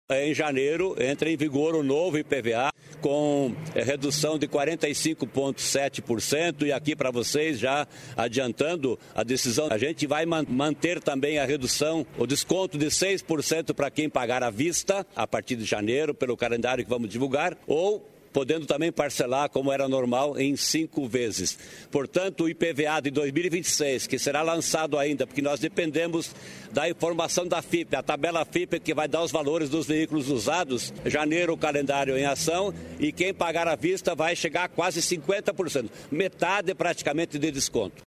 Sonora do secretário estadual da Fazenda, Norberto Ortigara, sobre descontos do IPVA